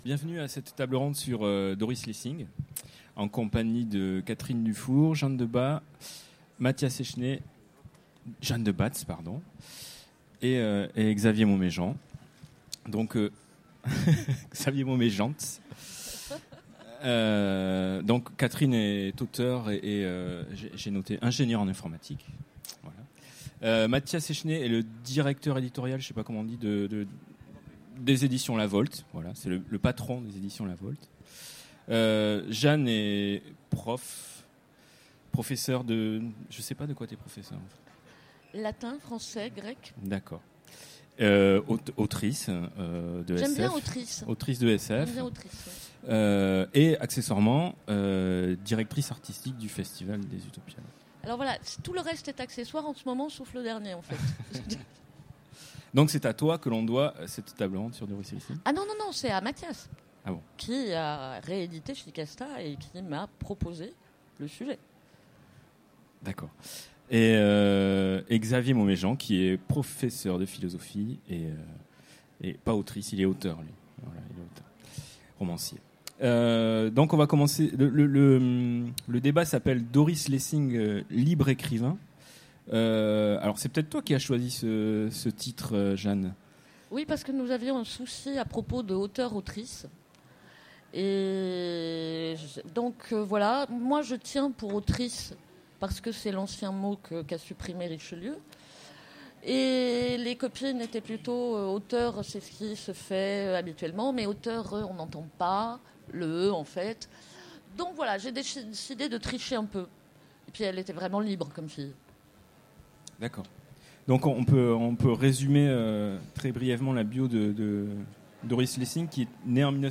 Utopiales 2016 : Conférence Doris Lessing, libre écrivain